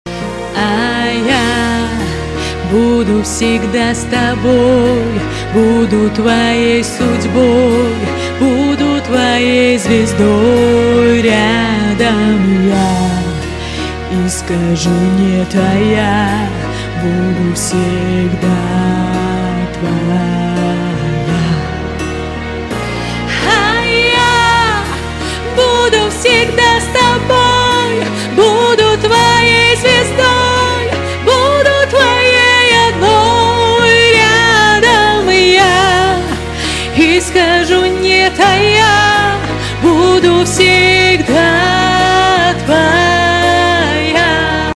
Touching and soulful song